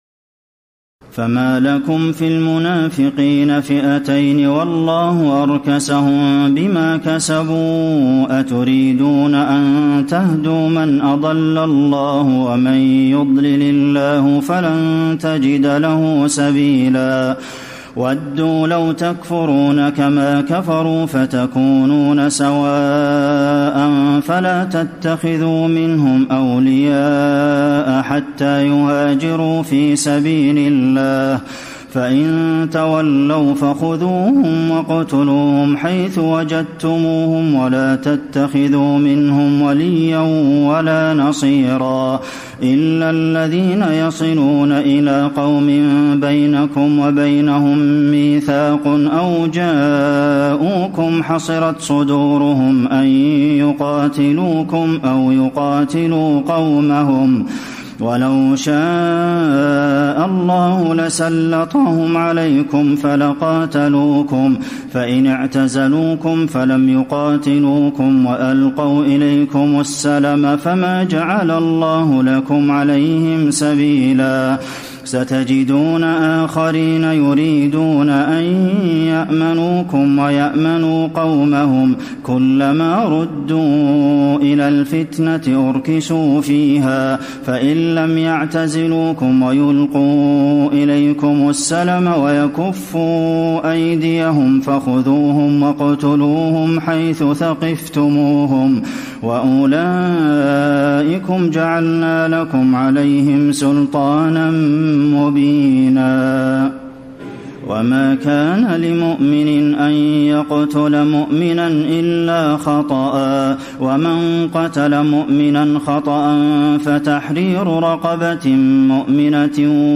تراويح الليلة الخامسة رمضان 1434هـ من سورة النساء (88-147) Taraweeh 5 st night Ramadan 1434H from Surah An-Nisaa > تراويح الحرم النبوي عام 1434 🕌 > التراويح - تلاوات الحرمين